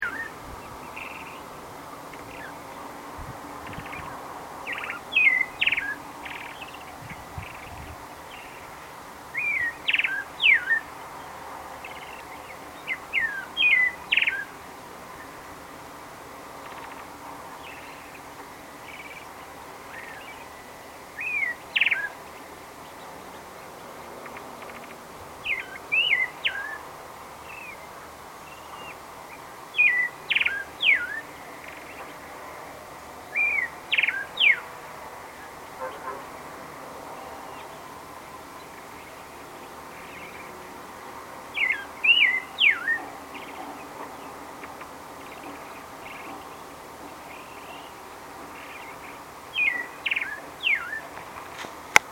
pale_thrush_2.MP3